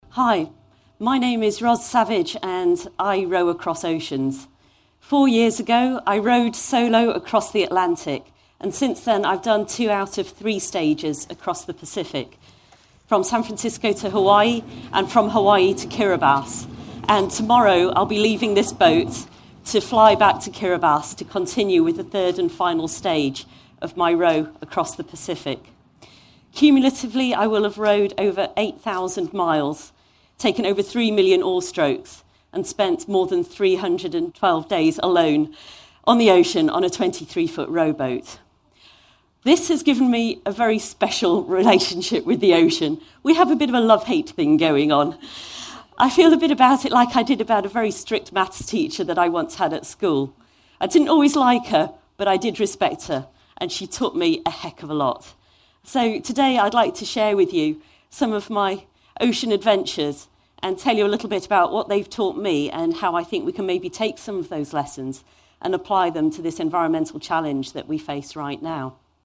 TED演讲：我为什么划船横渡太平洋(1) 听力文件下载—在线英语听力室